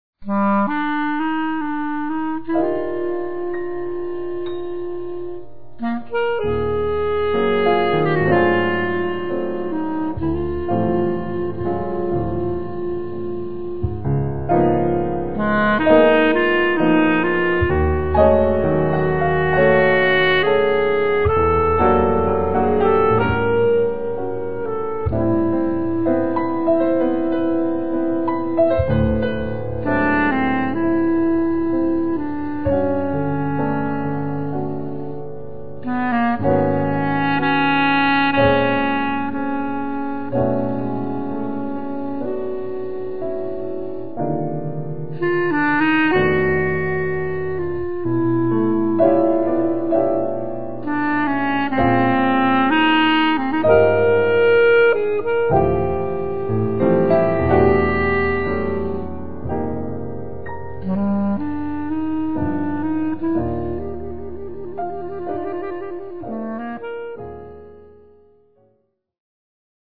duets with pianists